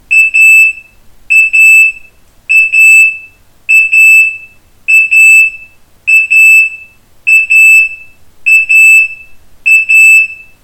• Lautstärke im Test: 96,7 dBA
abus-rm20-rauchmelder-alarm.mp3